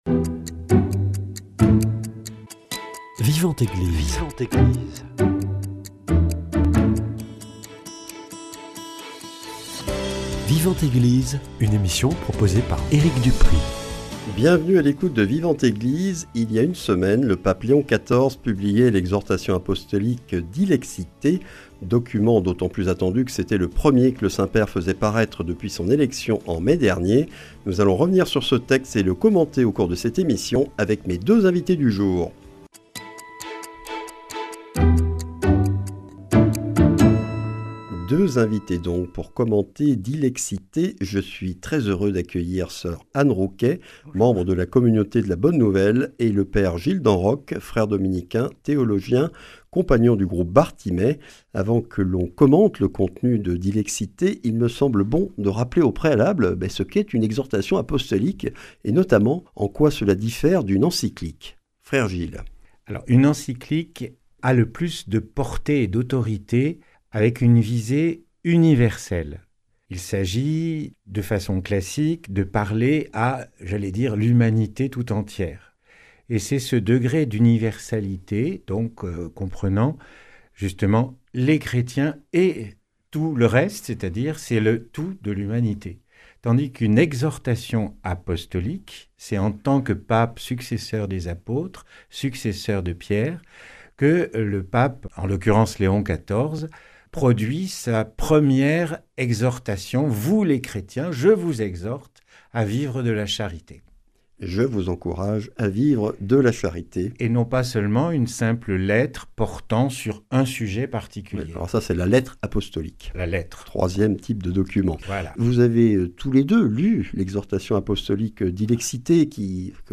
Commentaires et analyse